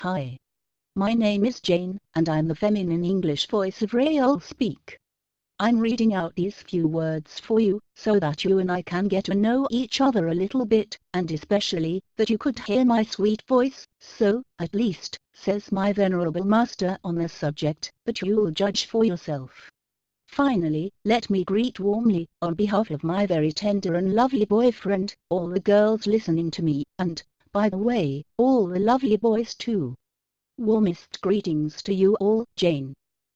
�couter la d�monstration de Jane; femme; anglais britannique; fournie avec Nuance (Anciennement Scansoft) OmniPage Pro (Version 14.0)